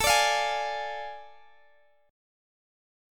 Listen to AM7sus4#5 strummed